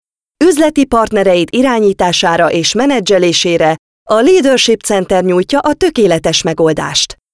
Native speaker Female 20-30 lat
Nagranie lektorskie